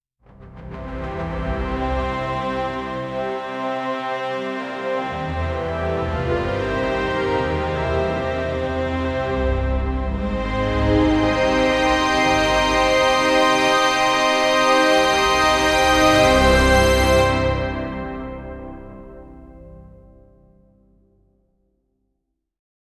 orchestral fanfare in C Major
tegna-studios-orchestral--fdcdlwa7.wav